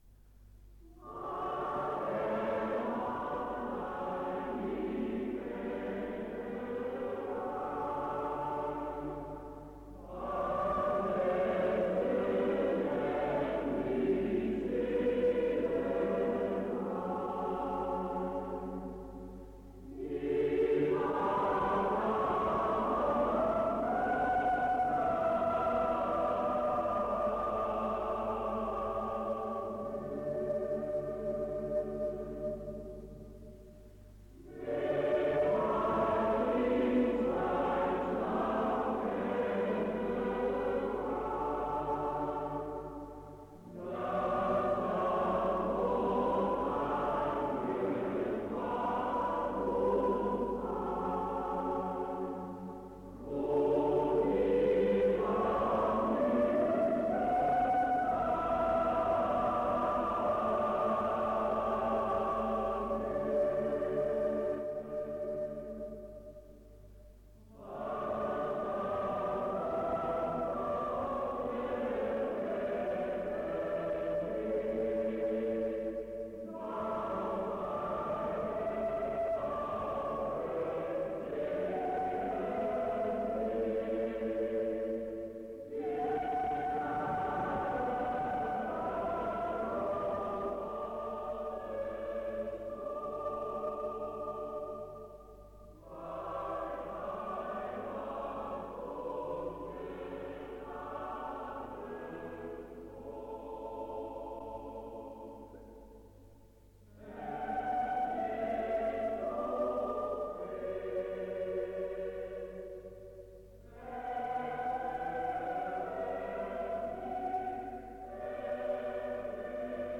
Deze website wordt opgebouwd uit het archief van het voormalige vermaarde Jongenskoor Cantasona uit Boxtel.